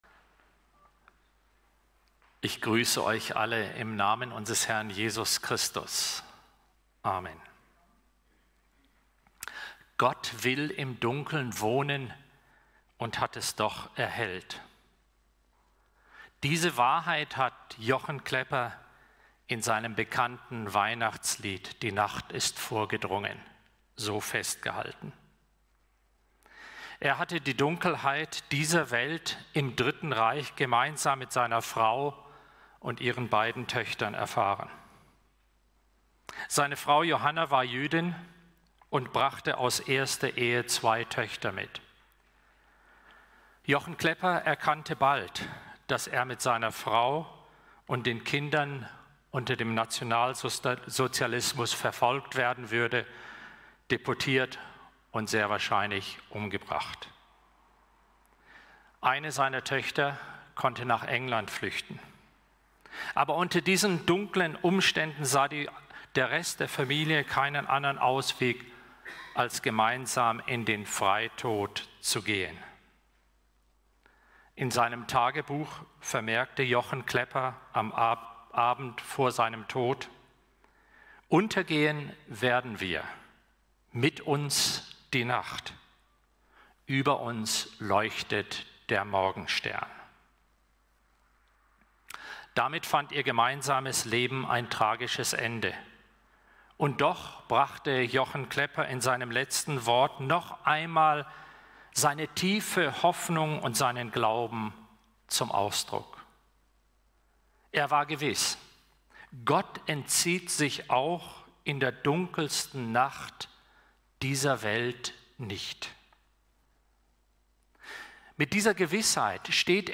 Gottesdienst_-Gott-will-im-Dunkeln-wohnen-und-hat-es-doch-erhellt-Amos-23-2.mp3